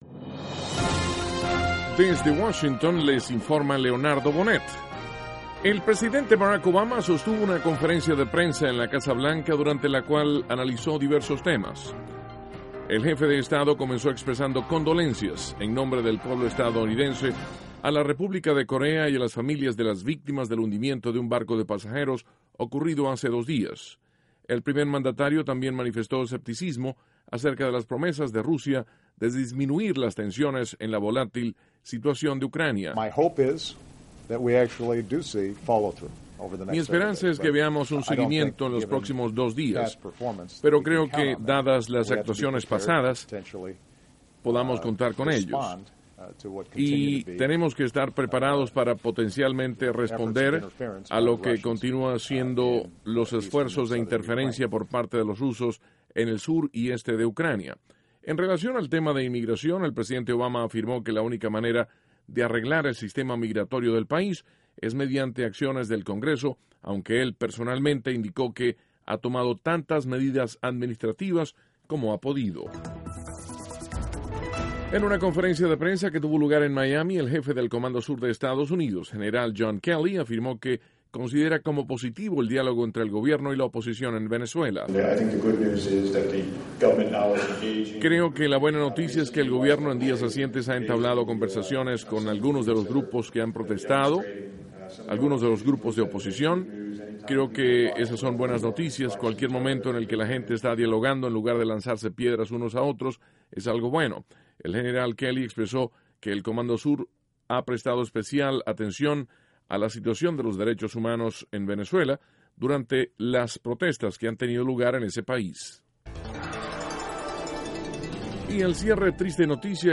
Duración: 3:00 1.-El presidente Obama desconfía de las promesas de Rusia en relación a Ucrania. (Sonido Obama) 2.- El General John Kelly, Jefe del Comando Sur, considera positivo el acercamiento entre gobierno y oposición en Venezuela. 3.- Fallece el escritor colombiano, Gabriel García Márquez, “El Gabo”.